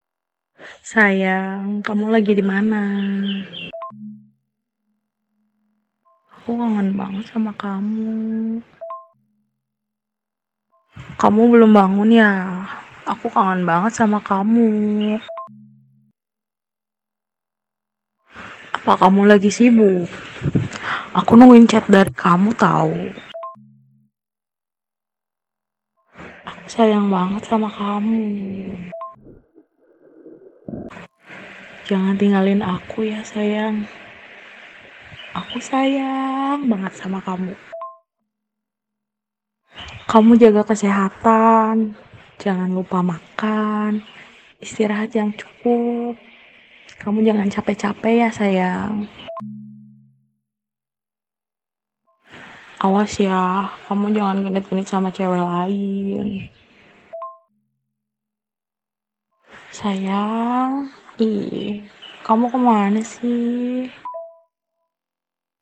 Nada dering suara Wanita Manja
Kategori: Nada dering
Keterangan: Ini adalah nada dering suara wanita bilang halo sayang lucu.
nada-dering-suara-wanita-manja-id-www_tiengdong_com.mp3